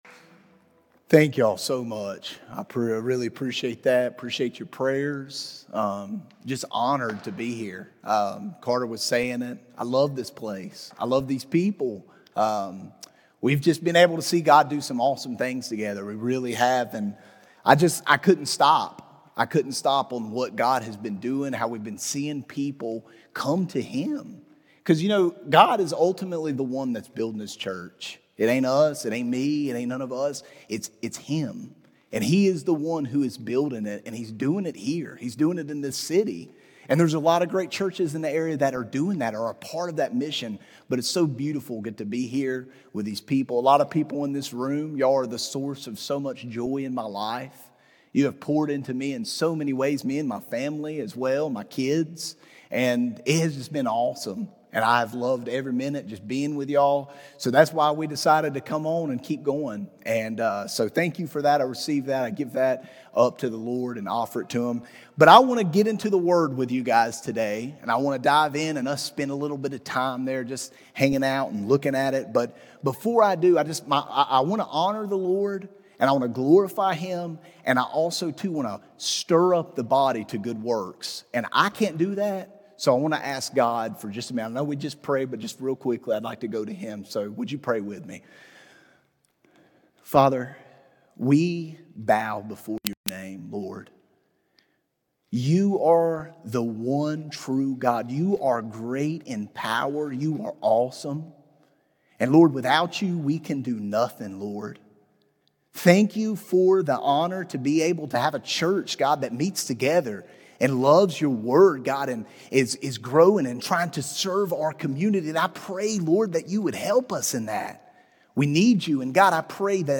Redemption Church Sermons How to Live a Fulfilled Life Aug 31 2025 | 00:40:57 1x 00:00 / 00:40:57 Subscribe Share Apple Podcasts Spotify Overcast RSS Feed Share Link Embed